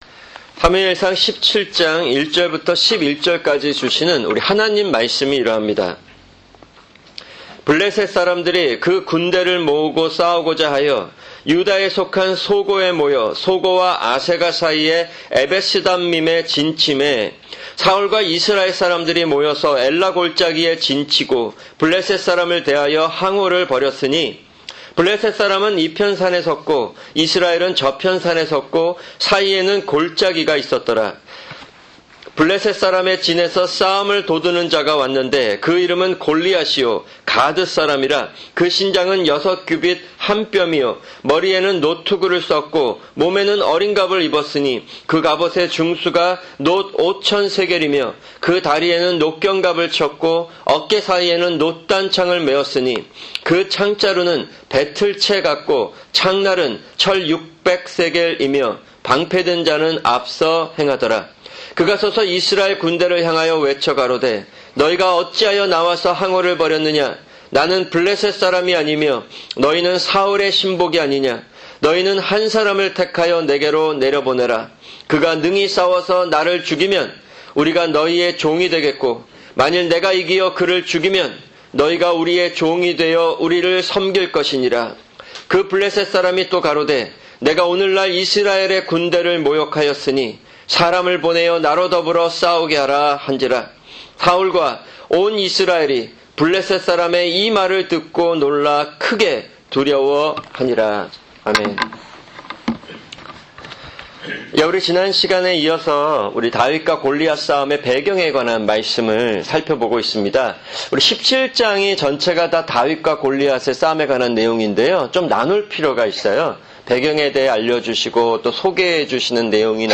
[주일 설교] 사무엘상(47) 17:1-11(2)